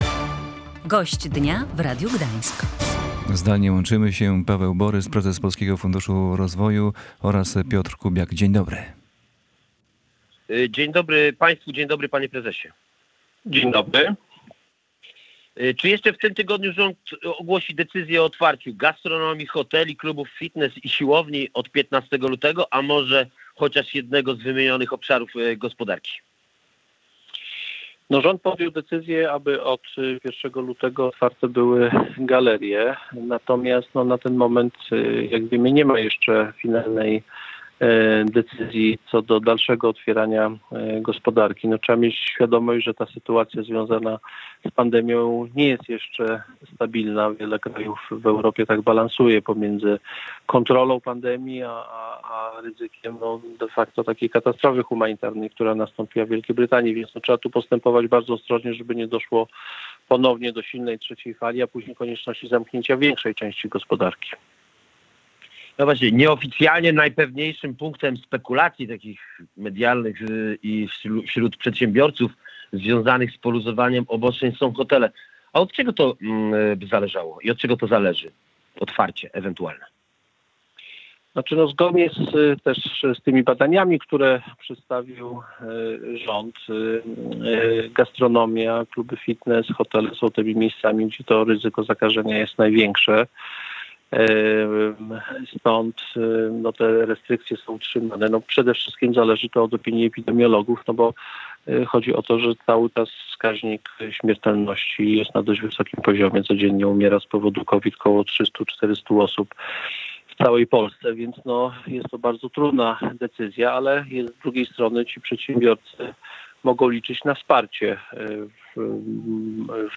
Na razie nie ma decyzji o zdejmowaniu kolejnych obostrzeń w gospodarce - mówił w Radiu Gdańsk Paweł Borys, prezes Polskiego